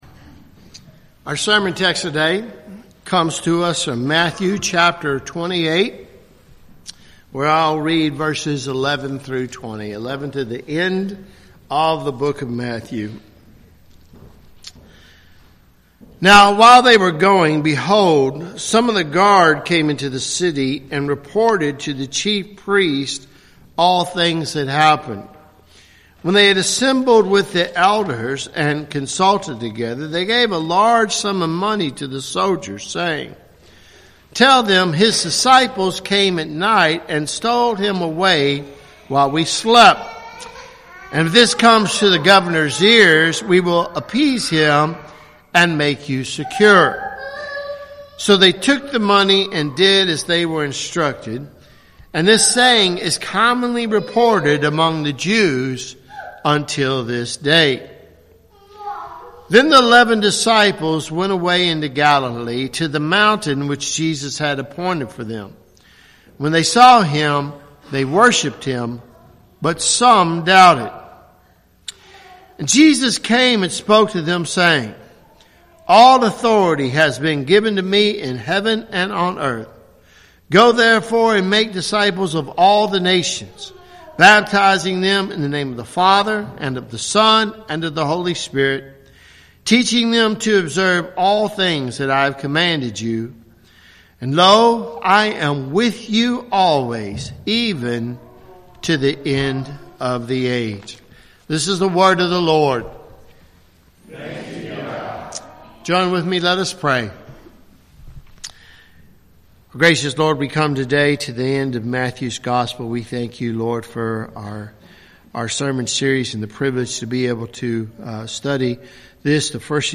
preached
at Christ Covenant Presbyterian Church, Lexington, Ky.